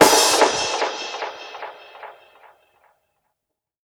INSNAREFX1-L.wav